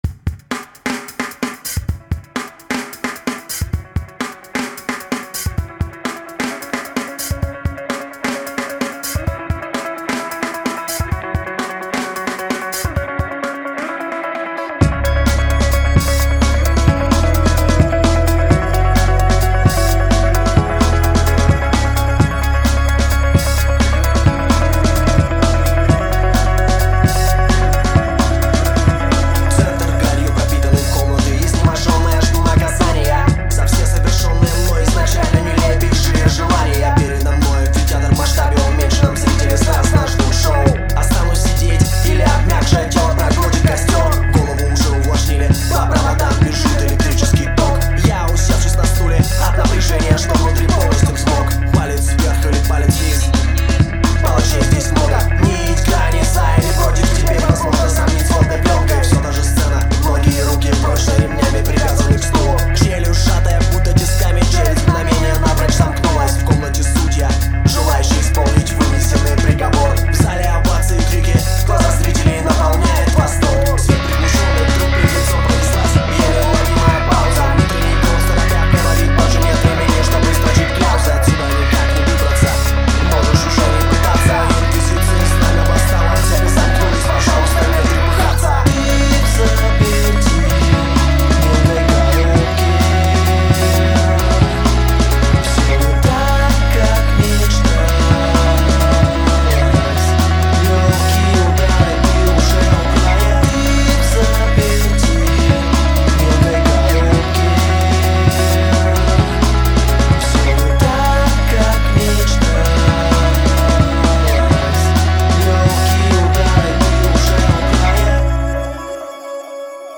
Сведение Рок